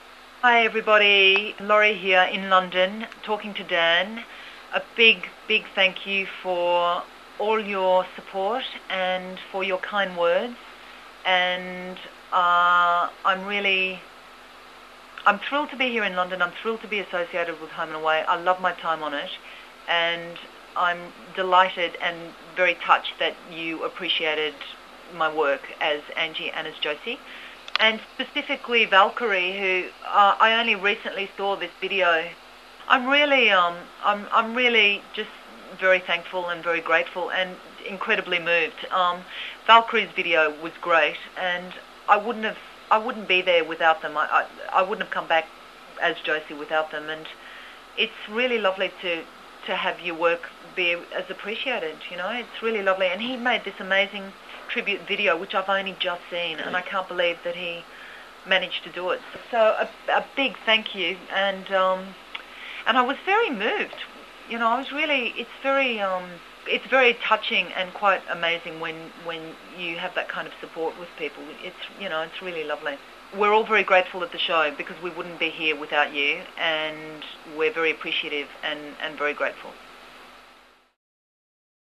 As part of her exclusive interview with the site, Laurie left a message to everyone at Back to the Bay.
laurie_message.mp3